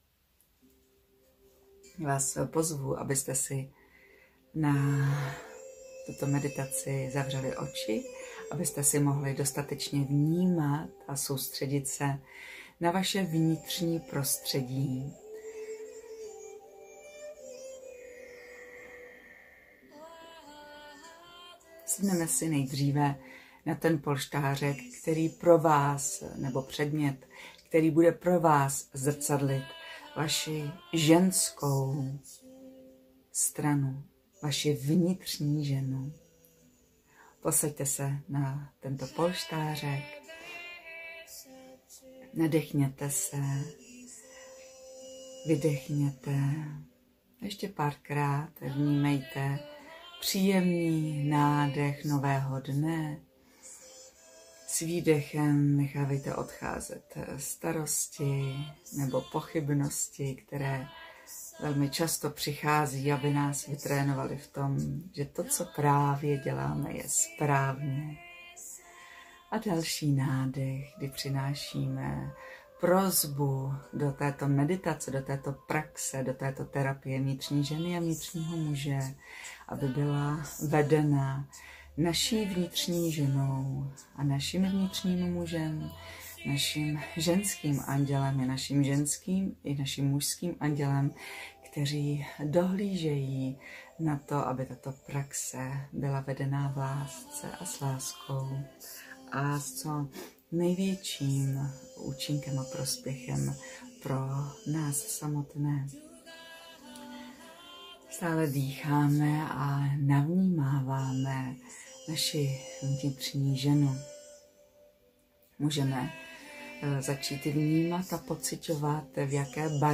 Koučink + řízená meditace
Meditace-vnitřní-žena-a-vnitřní-muž.mp3